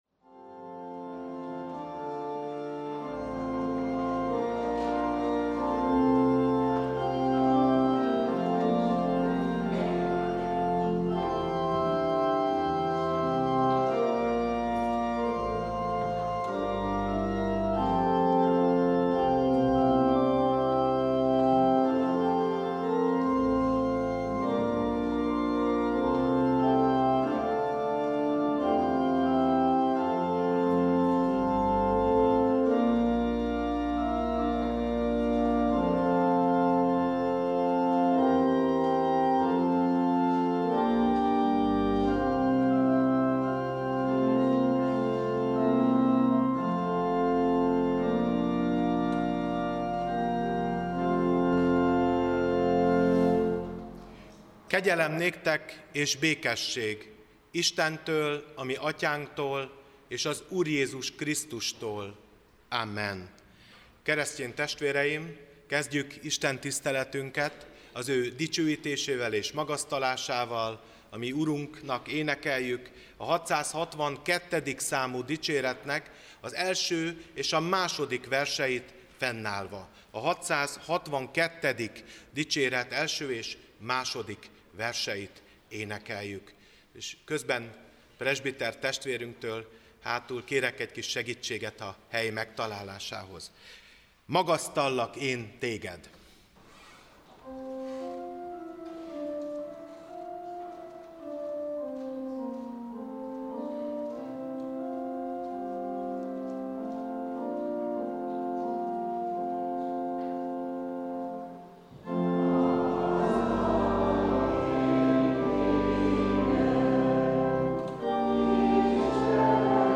Prédikációk 2026